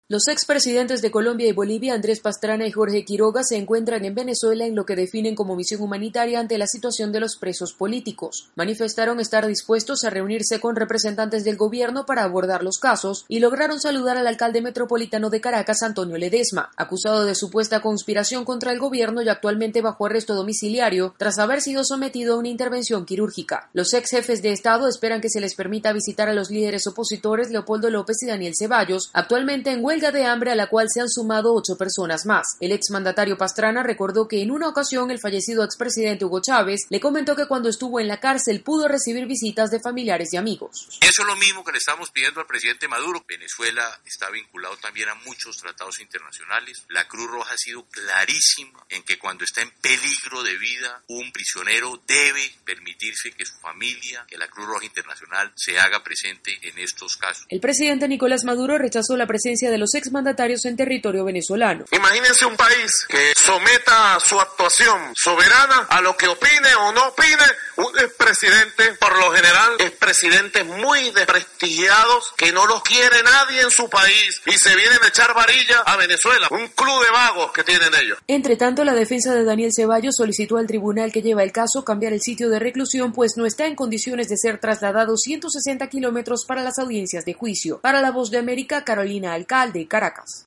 Dos ex presidentes suramericanos esperan que durante la jornada de este viernes se les permita visitar a los líderes opositores Leopoldo López y Daniel Ceballos, que están por cumplir una semana en huelga de hambre. Desde Caracas informa